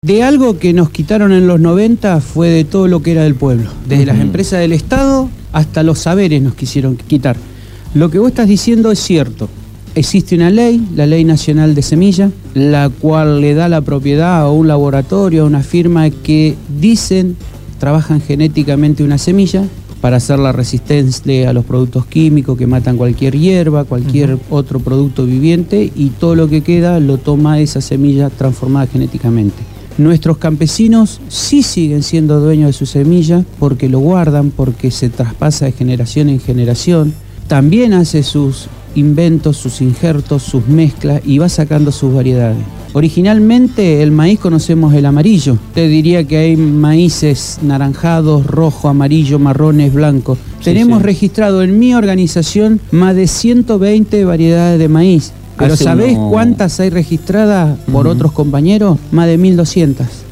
estuvo en el estudio de la Gráfica.
Entrevistado